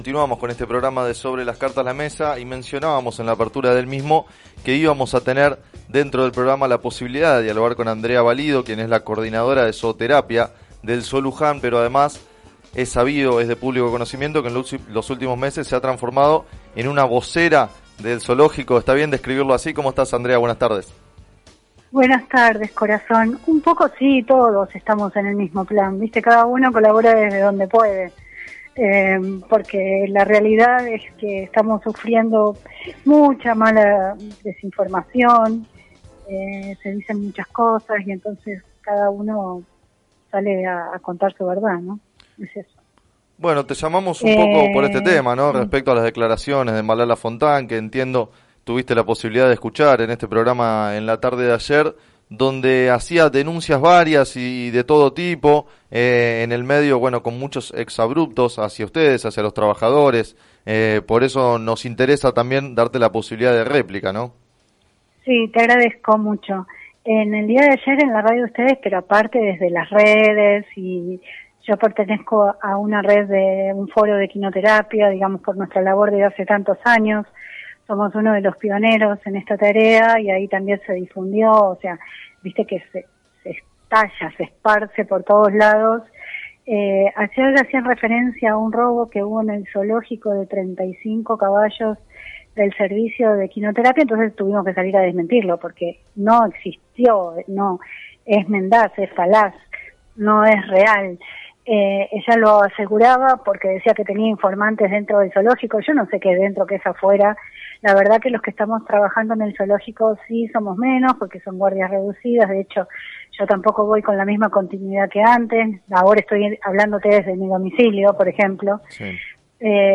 En declaraciones al programa “Sobre las Cartas la mesa” de FM Líder 97.7